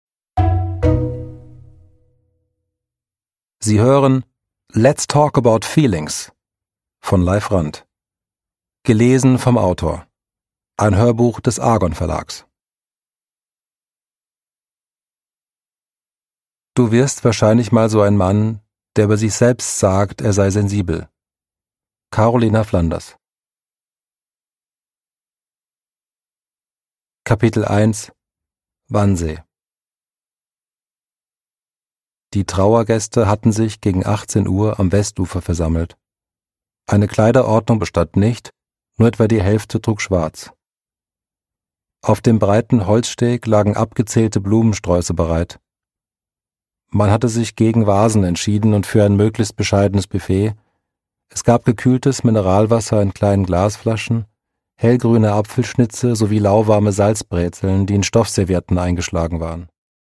Produkttyp: Hörbuch-Download
Gelesen von: Leif Randt